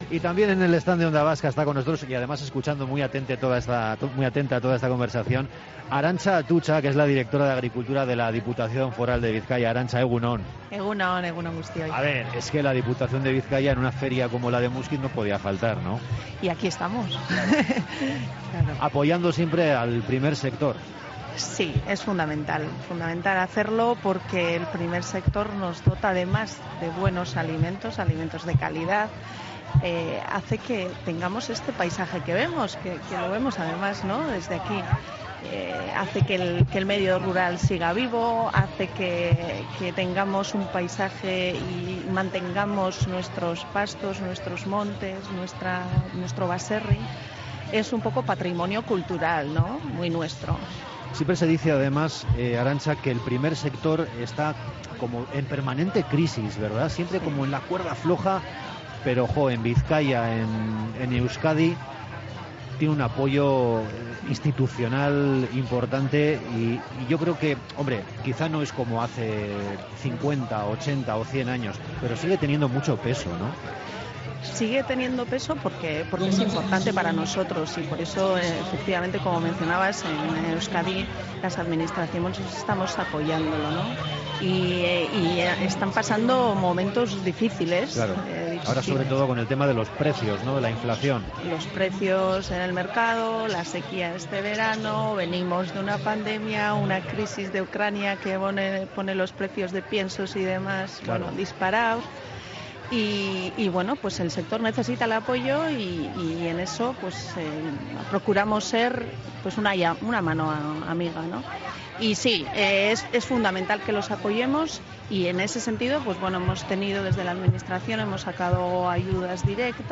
Entrevistas
Arantxa Atutxa, Directora de Agricultura de la Diputación de Bizkaia, en el stand de Onda Vasca en Muskiz